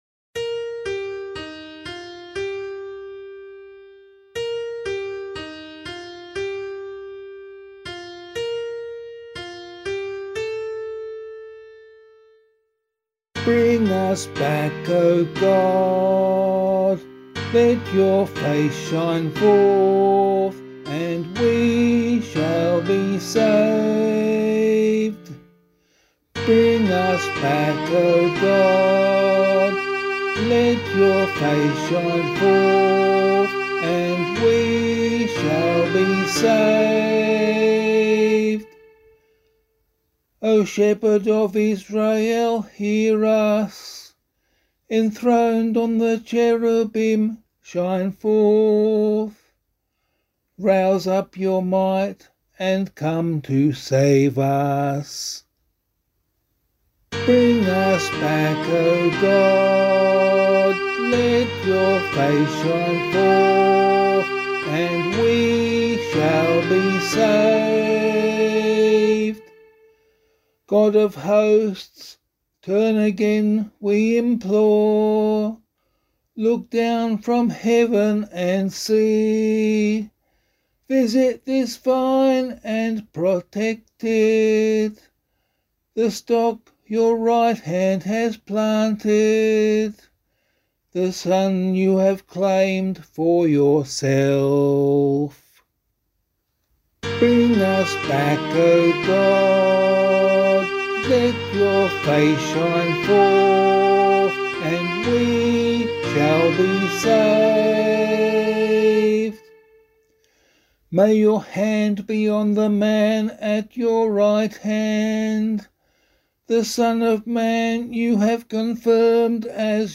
001 Advent 1 Psalm B [APC - LiturgyShare + Meinrad 3] - vocal.mp3